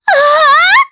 Epis-Vox_Happy4_jp.wav